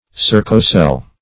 Circocele \Cir"co*cele\, n.